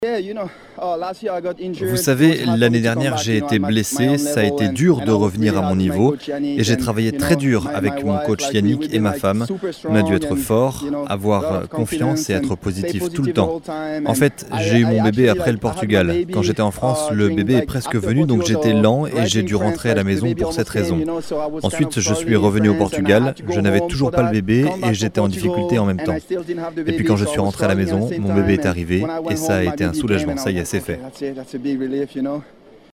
À peine sorti de l’eau, le Tahitien a réagi face caméra mais n’avait toujours pas réalisé son exploit.